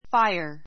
fáiə r ふァ イア